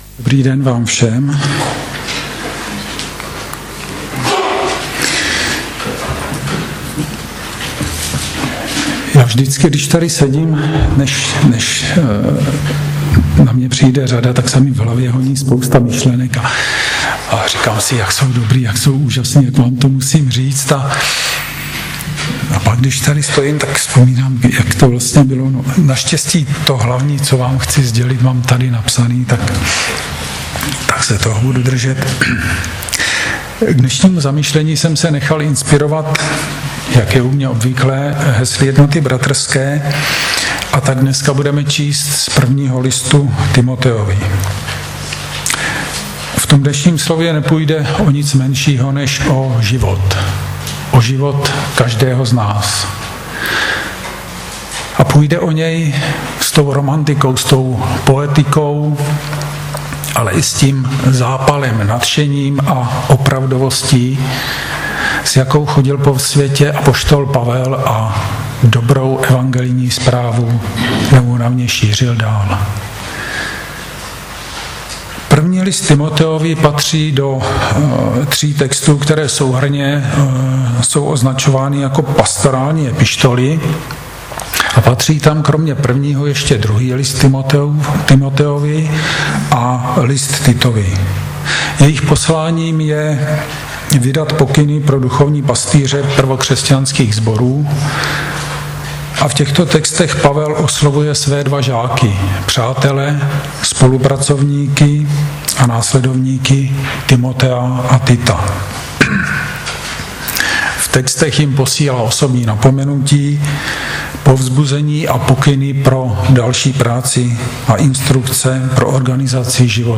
Kategorie: Nedělní bohoslužby